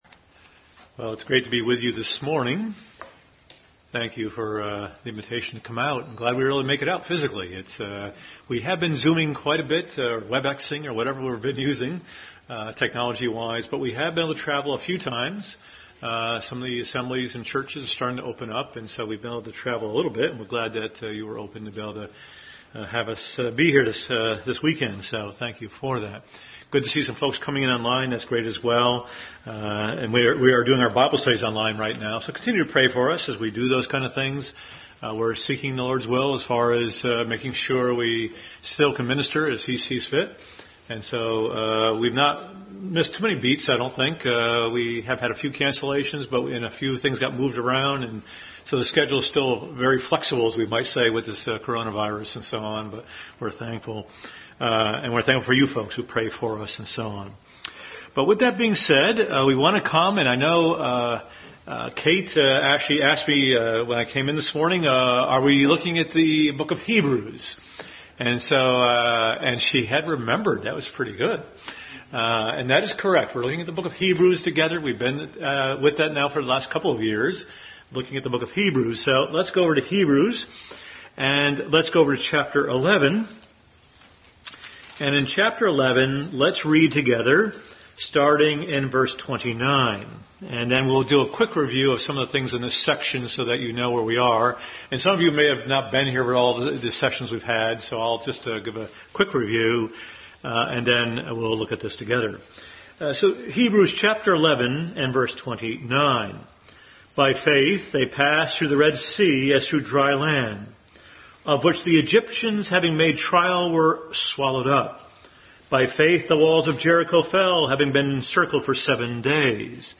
Bible Text: Hebrews 11:29-40 | Adult Sunday School class.